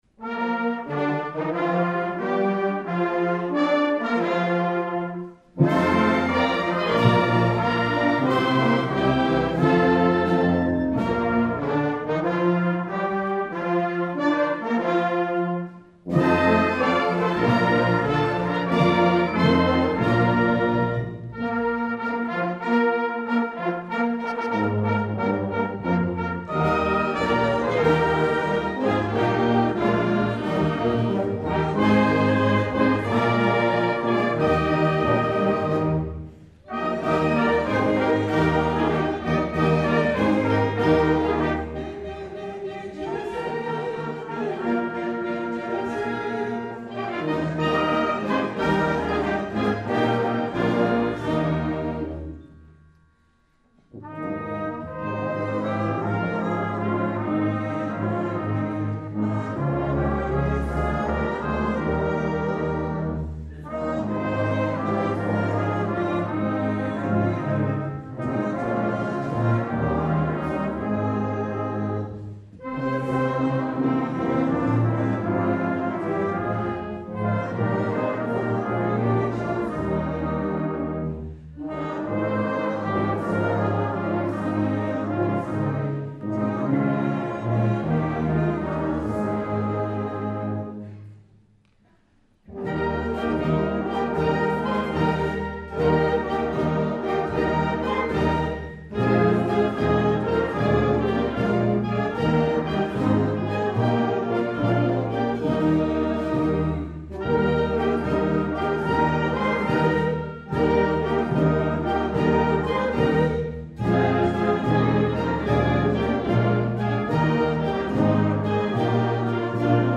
2009 Winter Concert
FLUTE
CLARINET
TRUMPET
PERCUSSION